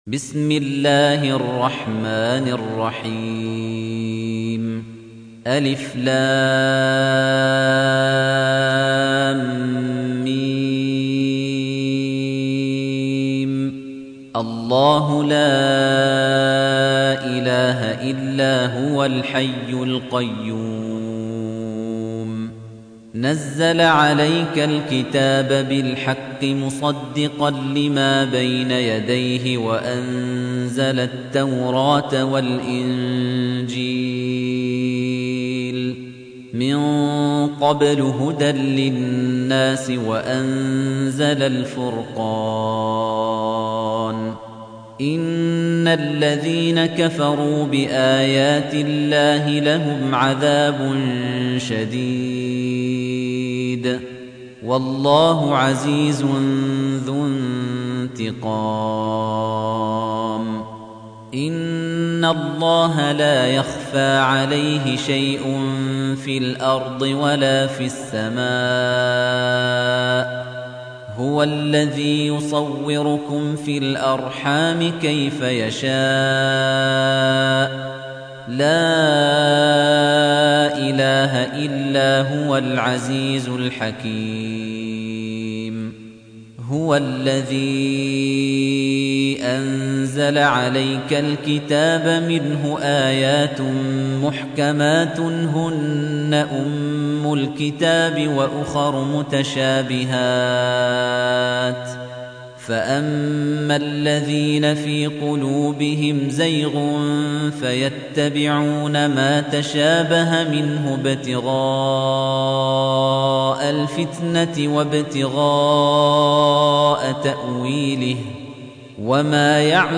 تحميل : 3. سورة آل عمران / القارئ خليفة الطنيجي / القرآن الكريم / موقع يا حسين